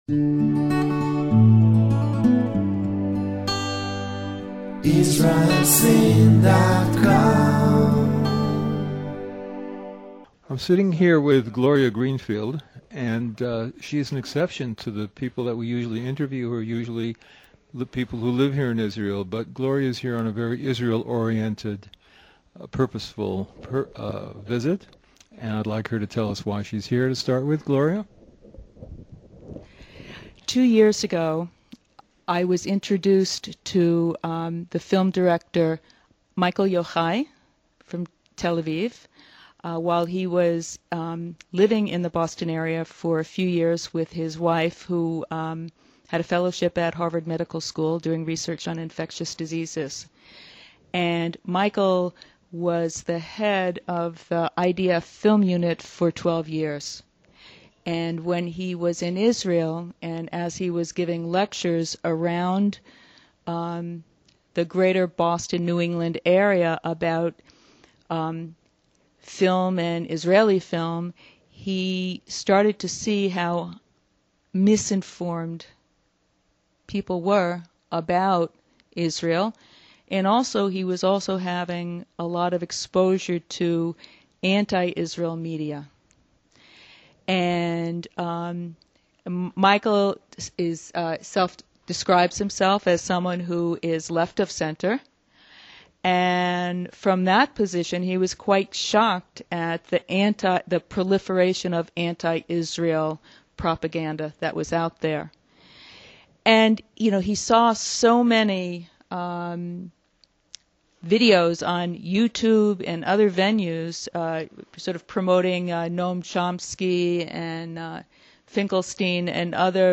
Interview on “A Case For Israel”